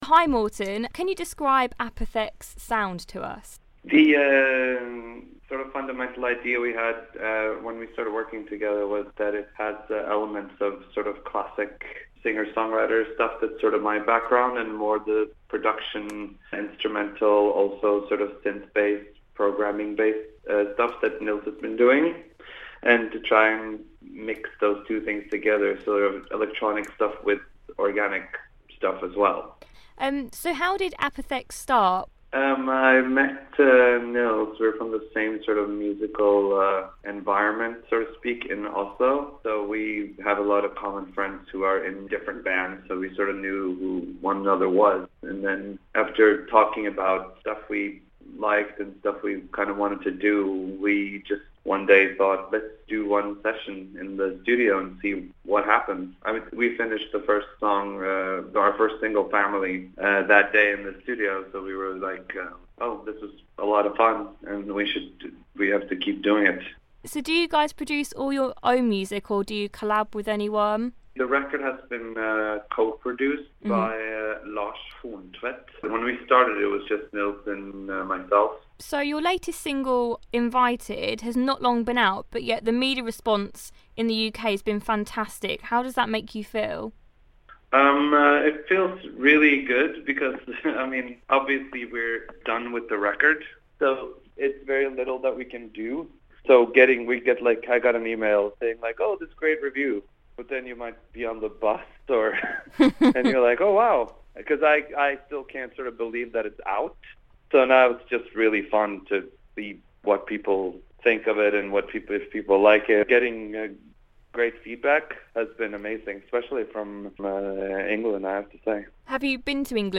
INTERVIEW: APOTHEK chat about their new single 'Invited' and how they're forming a fanbase in England